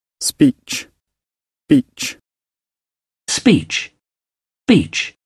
In fact, the resulting unaspirated plosive sounds very like English /g/, so that this name sounds more like /s/ + got than like /s/ + cot.
But for confirmation, here is the word speech from both the Oxford and Cambridge online dictionaries, followed by the same recordings with /s/ removed – which sounds like beach:
oxf_camb_speech_beach.mp3